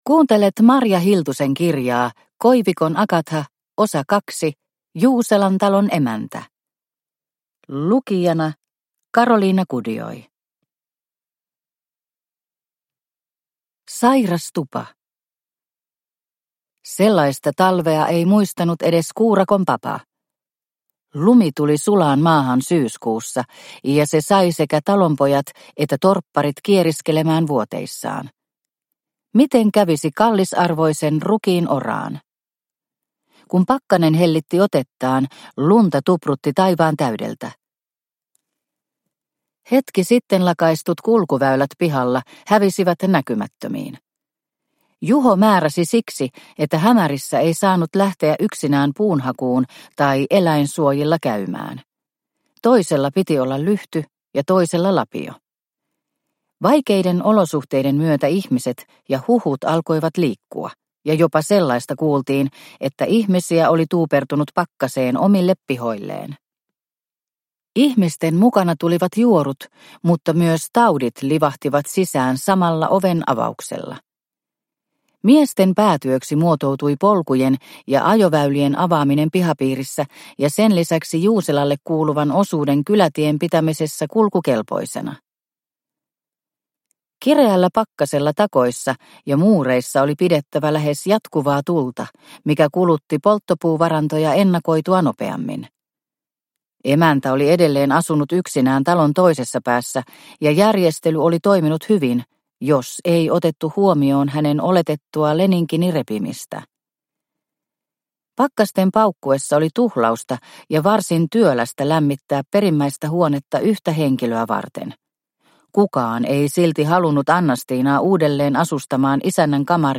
Juuselan emäntä – Ljudbok – Laddas ner